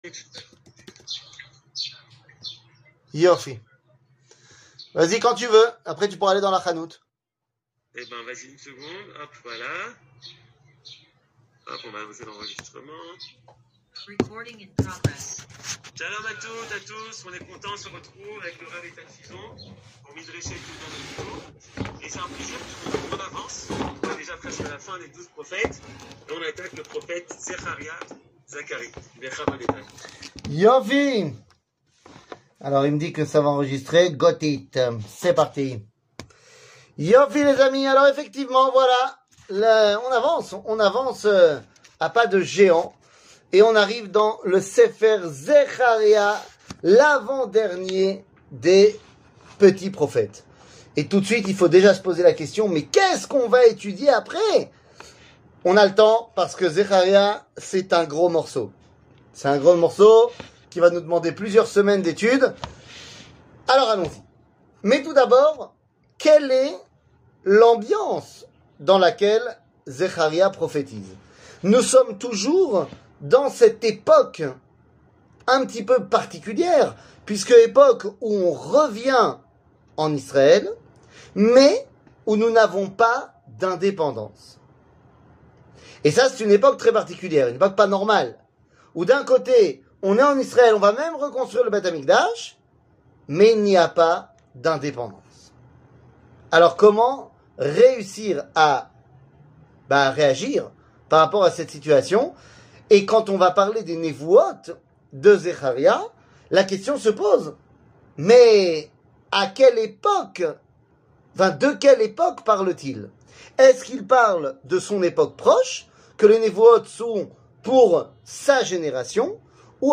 קטגוריה r 00:48:30 r שיעור מ 10 מאי 2022 48MIN הורדה בקובץ אודיו MP3